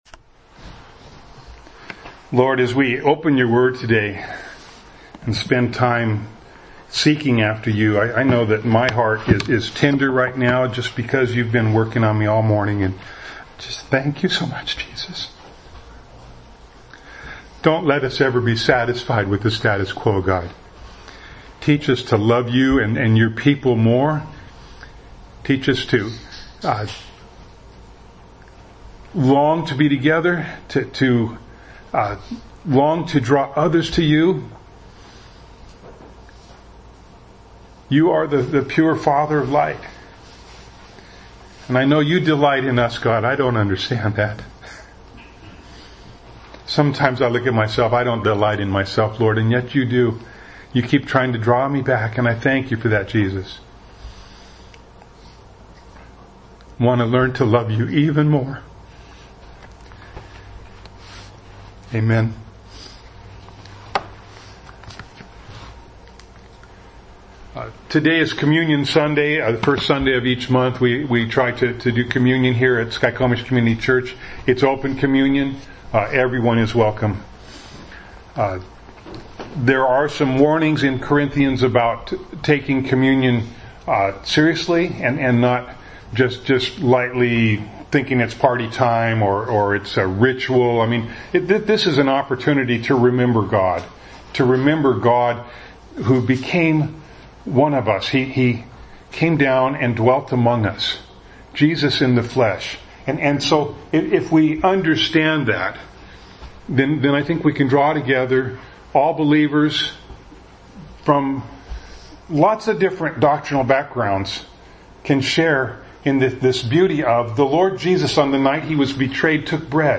James 5:13 Service Type: Sunday Morning Bible Text